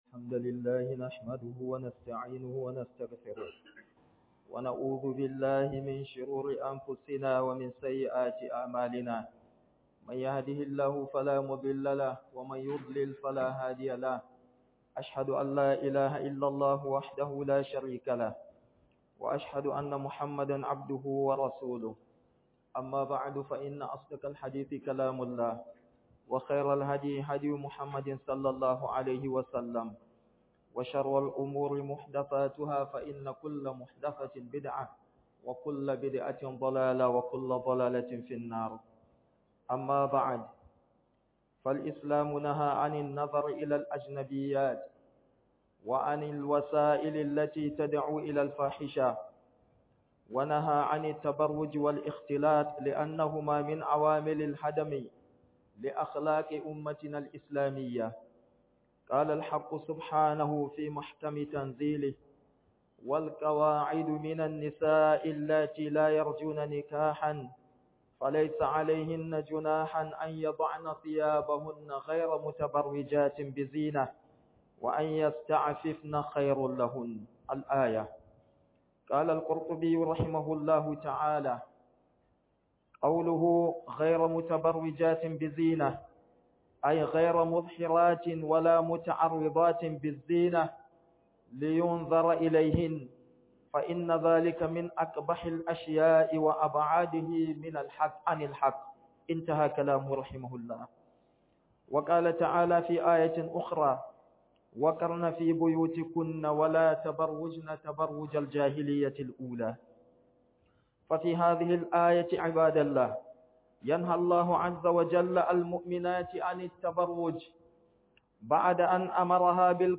A KIYAYI YIN TABARRUJI - Huduba